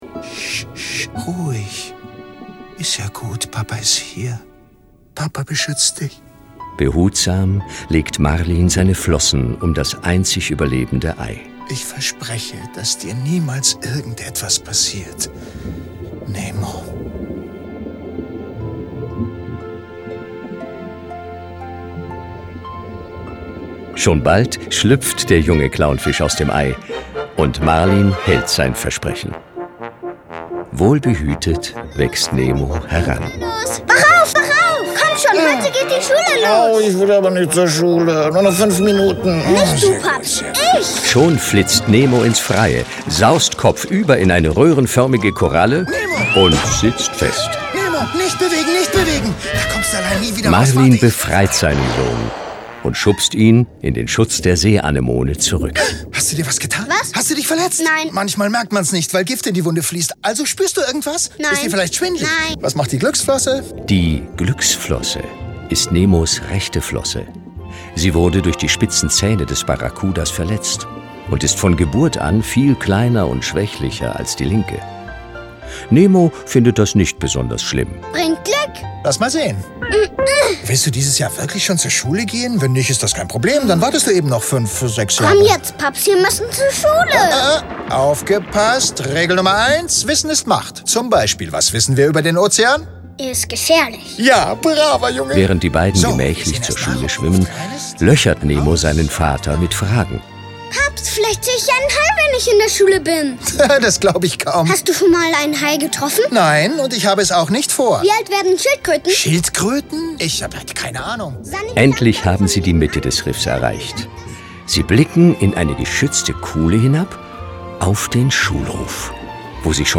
Hörspiel mit Liedern